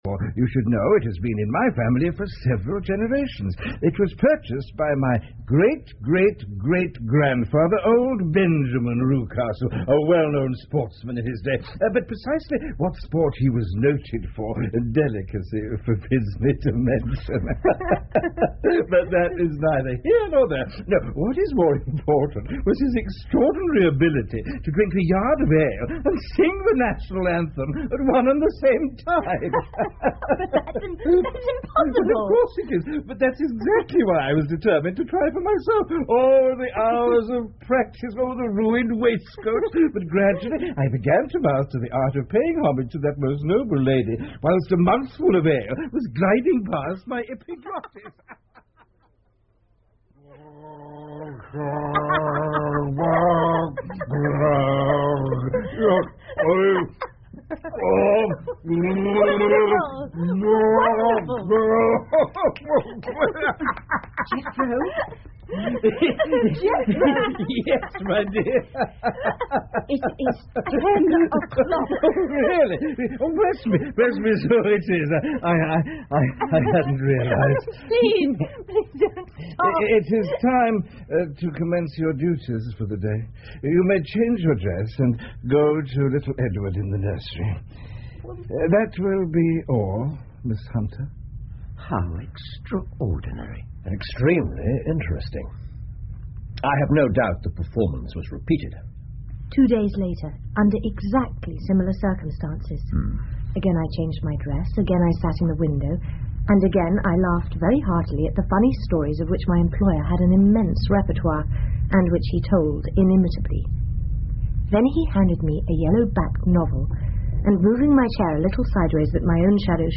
福尔摩斯广播剧 The Copper Beeches 5 听力文件下载—在线英语听力室